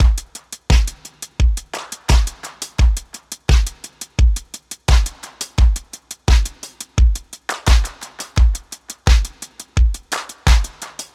Index of /DESN275/loops/Loop Set - Futurism - Synthwave Loops
BinaryHeaven_86_FullDrums.wav